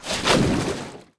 • 声道 立體聲 (2ch)